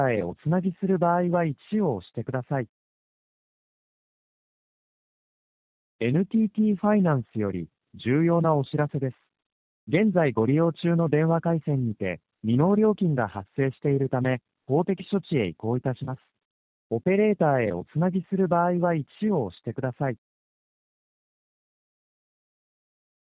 特殊詐欺犯人が使用している自動音声 NTTファイナンス騙り（「未納料金が発生しています」の巻） NTTファイナンスを装い、料金未納に関する問合せのためにオペレーターにつなごうとさせる自動音声を聞くことができます。